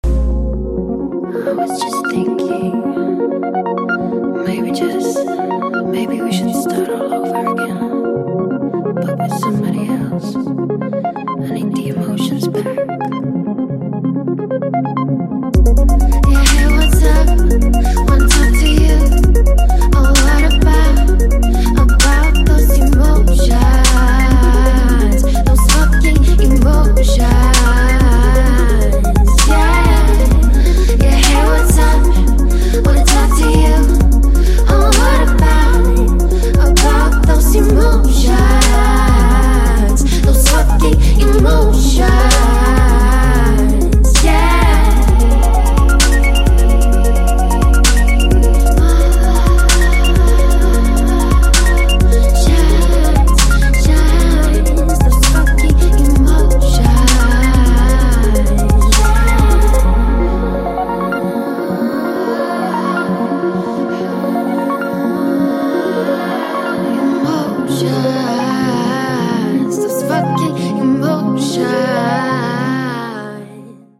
• Качество: 128, Stereo
женский голос
спокойные
красивая мелодия
vocal
спокойная музыка
Стиль: ChillOut, Hip-Hop